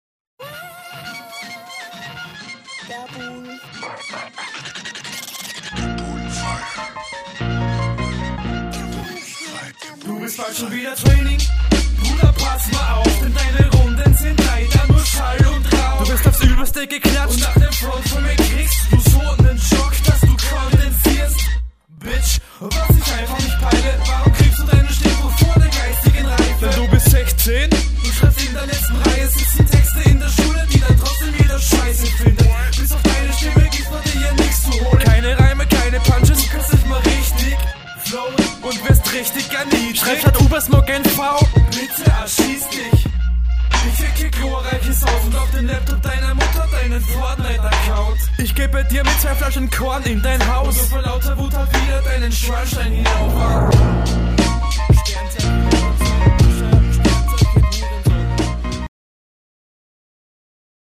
Beat hebt sich mal von vielen anderes Beats ab die ich kenne lol.
Soundqualität is chillig. Könnte etwas besser gemischt sein. Geile Breaks in den Parts. Flow sitzt!